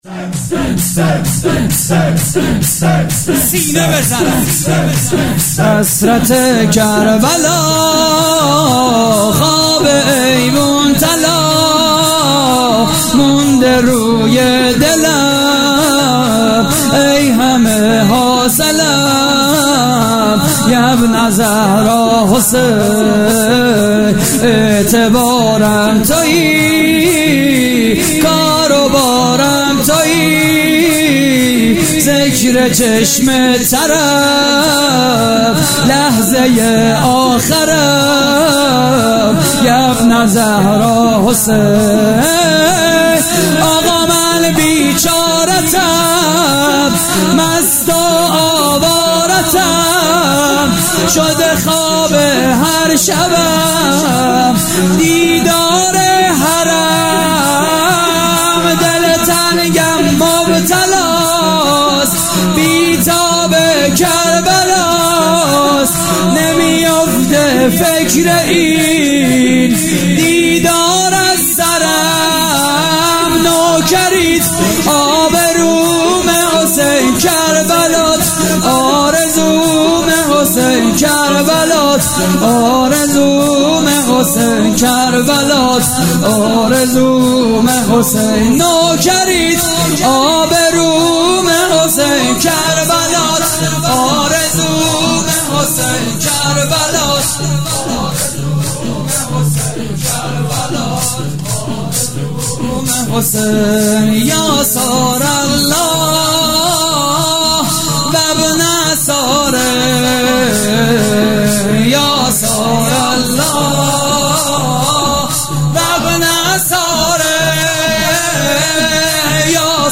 مراسم مناجات و وداع با ماه مبارک رمضان / هیئت مکتب المهدی (عج)؛ نازی آباد - 23 خرداد 97
صوت مراسم:
شور: حسرت کربلا؛ پخش آنلاین |